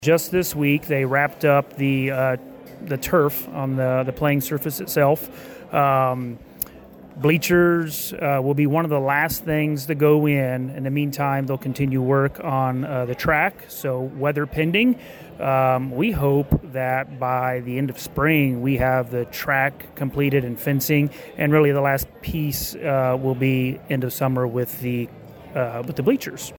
During an interview with KVOE News just ahead of the gala